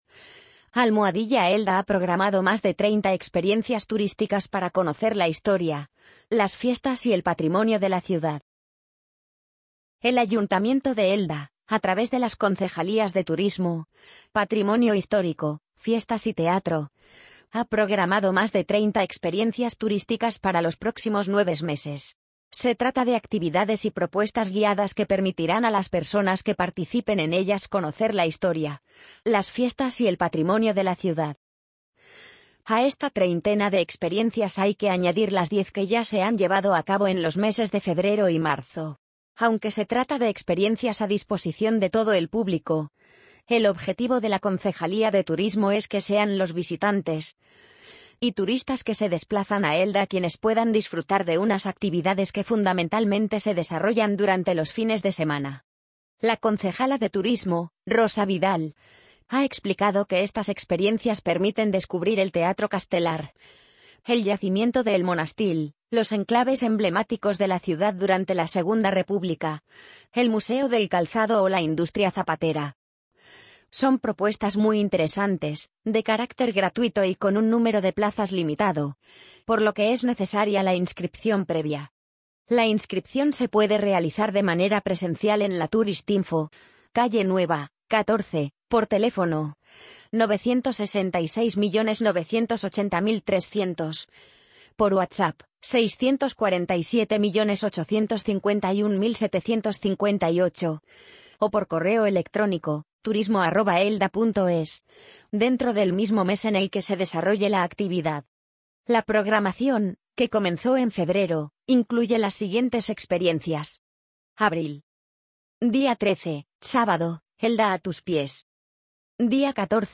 amazon_polly_72052.mp3